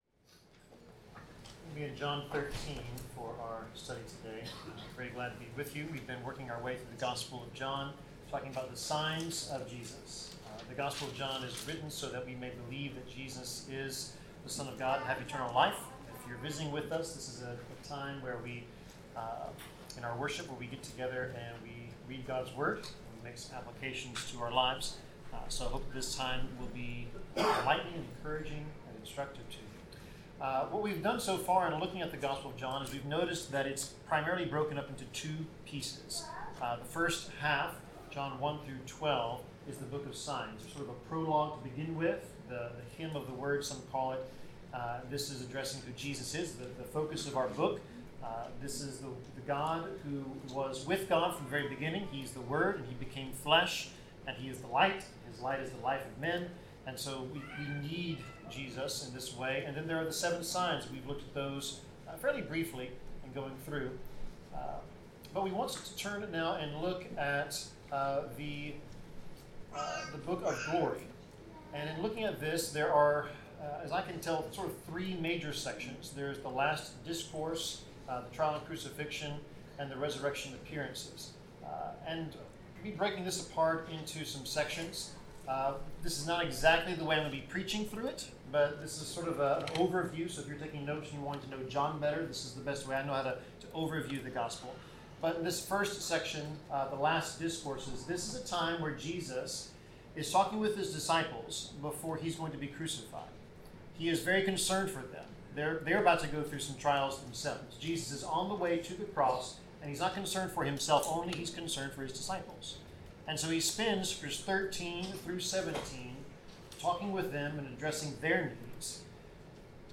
Passage: John 13 Service Type: Sermon